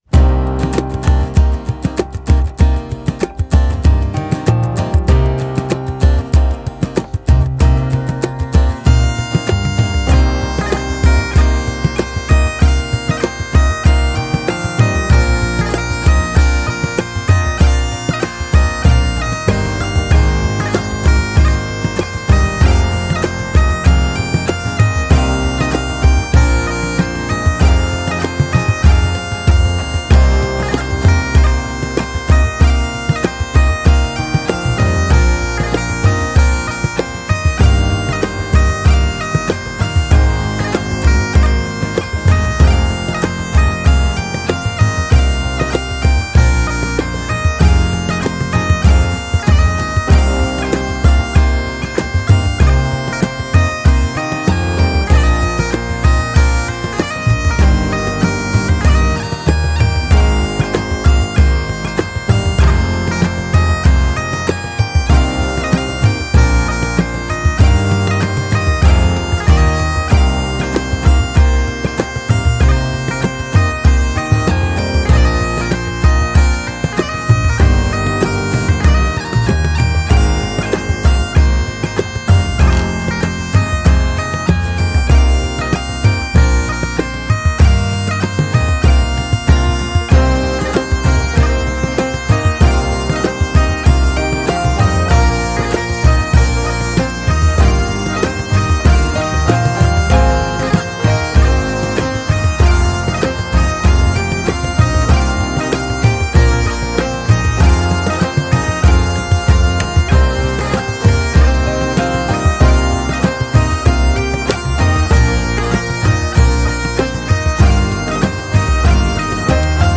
The music you can hear is a March.